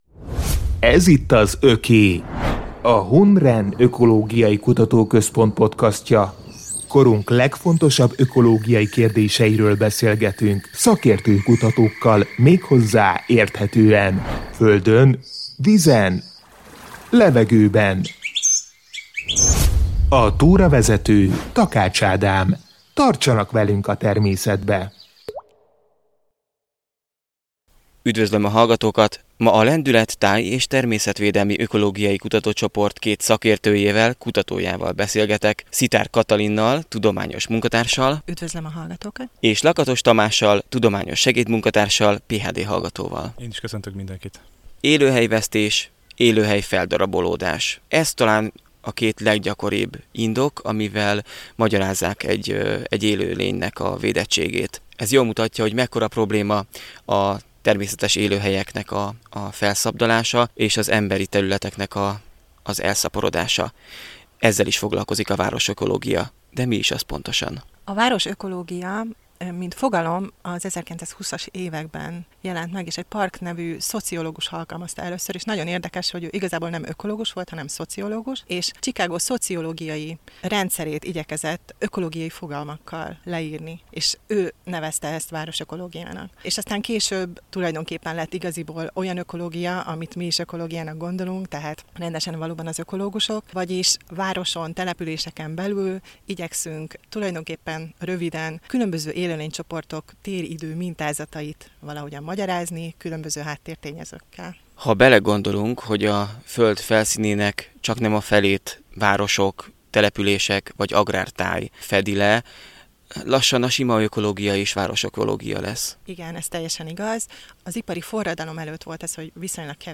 A természetet, környezetünket, így a jövőnket érintő legfontosabb ökológiai kérdésekről beszélgetünk a témákban jártas tudósokkal.